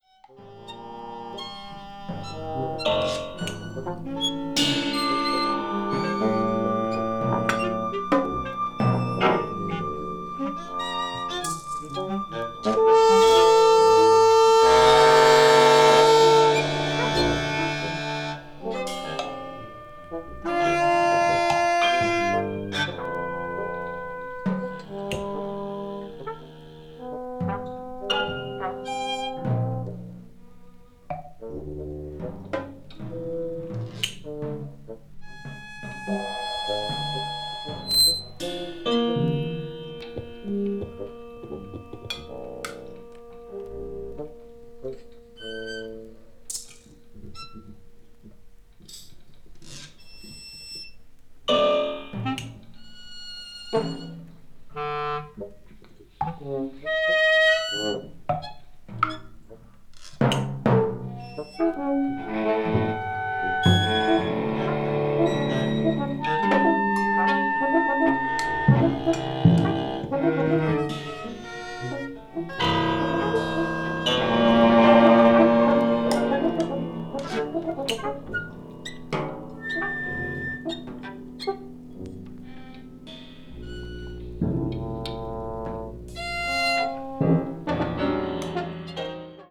media : EX-/EX-(わずかなチリノイズ/一部軽いチリノイズが入る箇所あり)